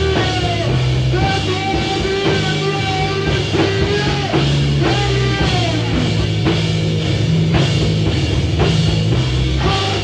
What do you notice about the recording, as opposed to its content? This analog audience audio is the only source for this show.